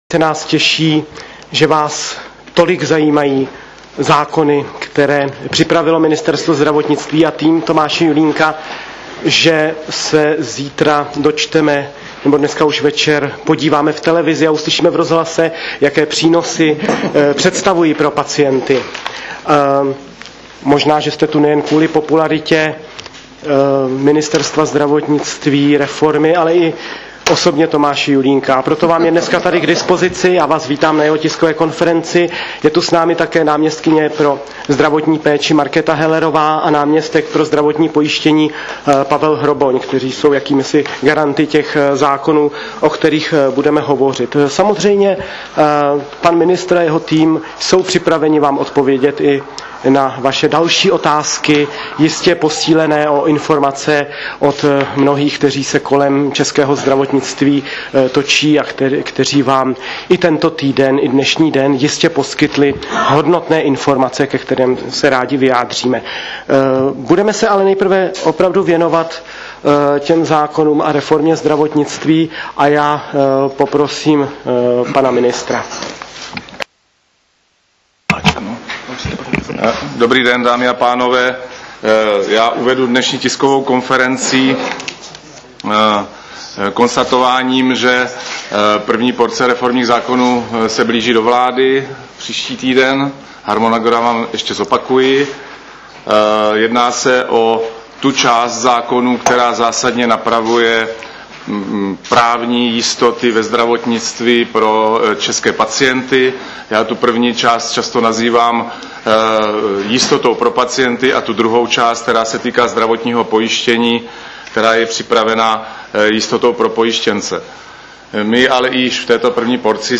Zvukový záznam tiskové konference o nových zákonech